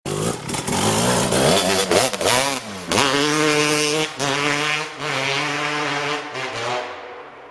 ŚCIĄGNIJ Sound Honda Cr250